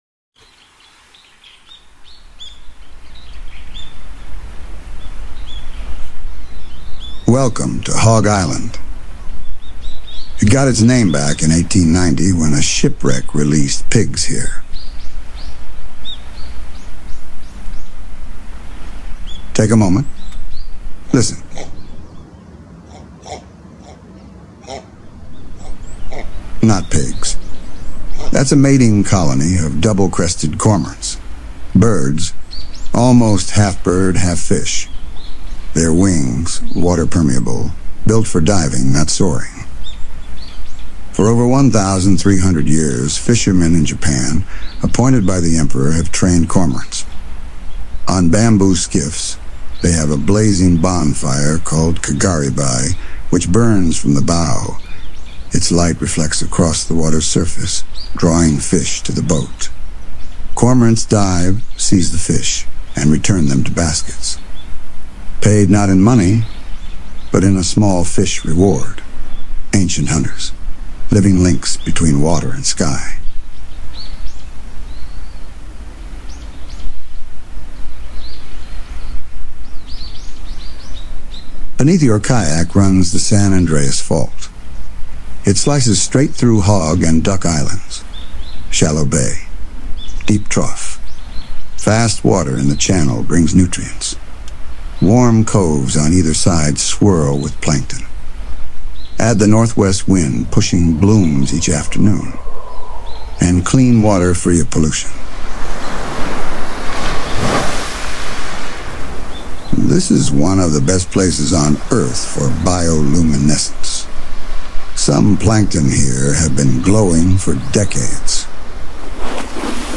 A hands-free audio guide—voiced by Robo (Burt) Reynolds—adds playful storytelling and subtle direction along the way.
CLICK THIS LINK TO HEAR A SAMPLE OF OUR AUDIO GUIDE VOICED BY ROBO REYNOLDS